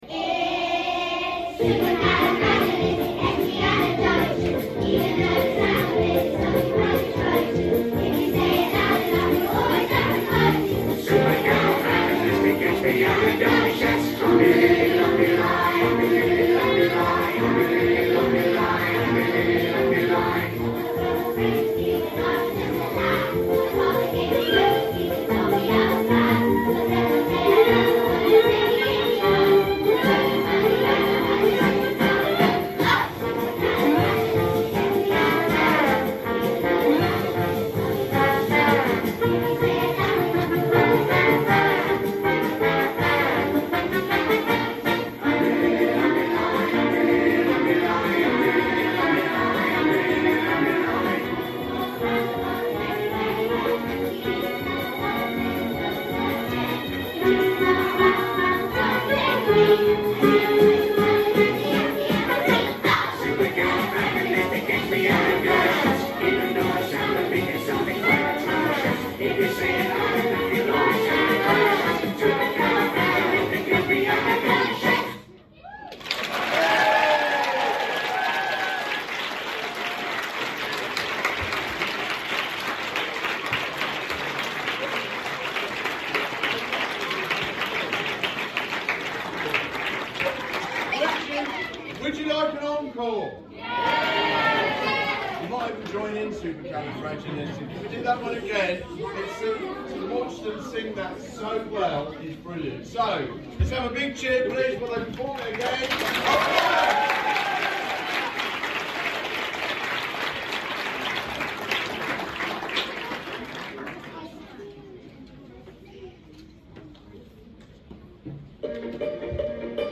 Supercalifragilisticexpialidocious | Year 2/3 Choir